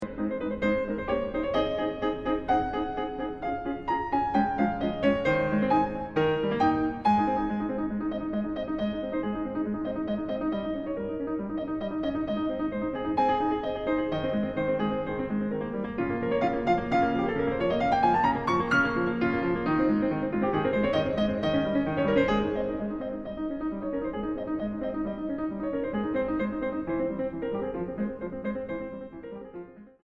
Música mexicana para piano de los siglos XX y XXI.
piano